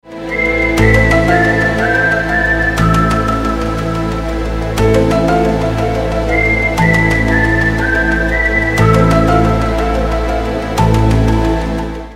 • Качество: 256, Stereo
OST
загадочные